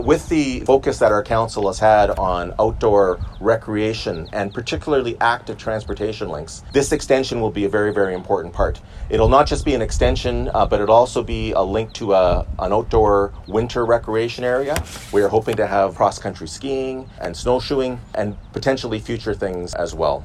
Mayor Mitch Panciuk called the dedication a fitting tribute to someone who was “ahead of their time” when it comes to environmental activism, noting that the trail extension will be more than just somewhere for people to walk or ride.